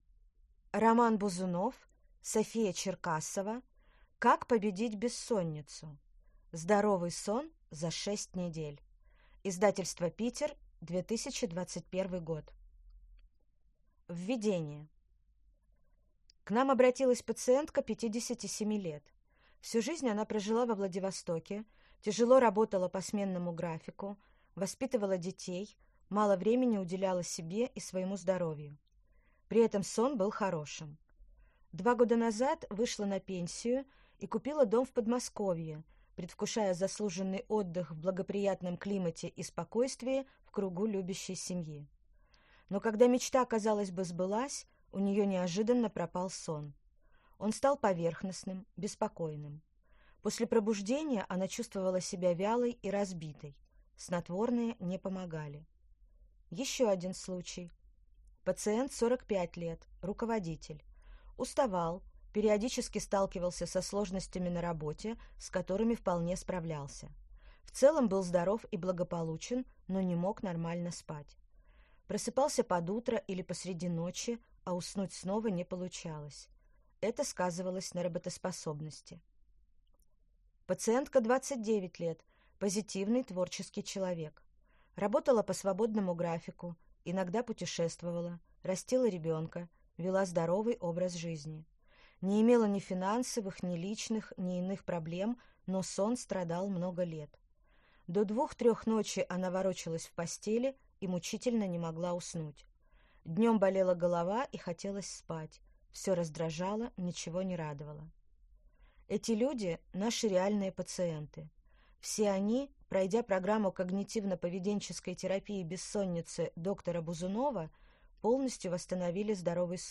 Аудиокнига Как победить бессонницу? Здоровый сон за 6 недель | Библиотека аудиокниг